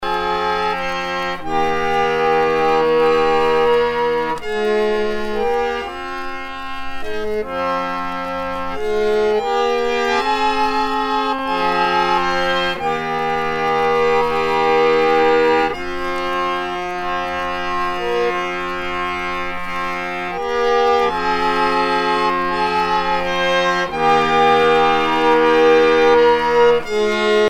Sea chanteys and sailor songs